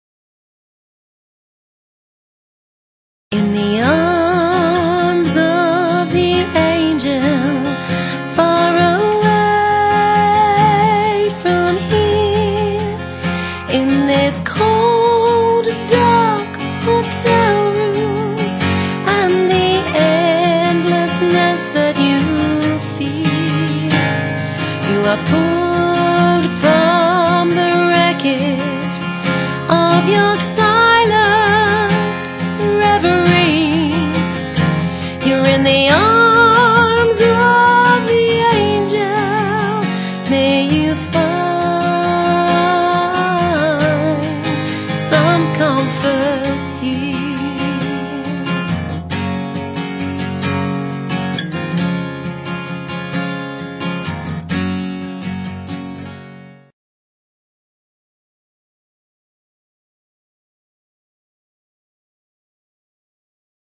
singing and playing acoustic guitar as a solo performer